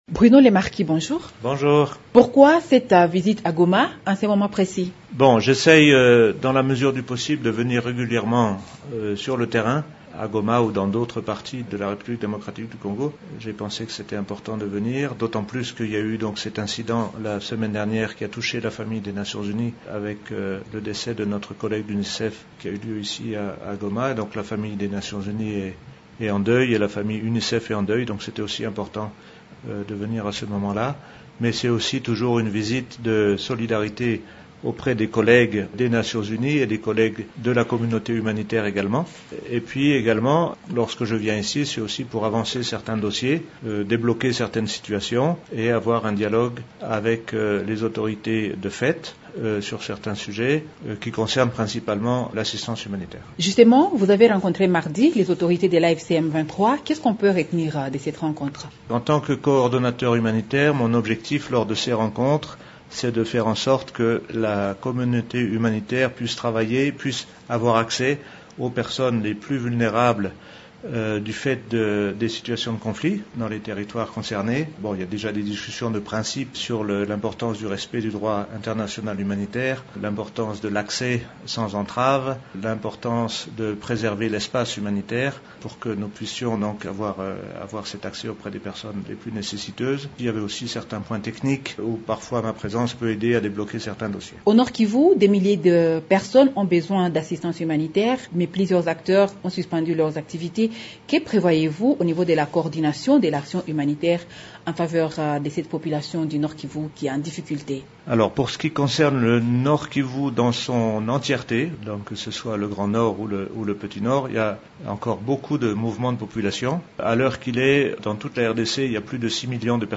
Dans un entretien accordé mercredi 18 mars à Radio Okapi lors de son séjour à Goma, il indique que la fermeture de cet aéroport complique la tâche aux humanitaires, voulant intervenir dans cette contrée.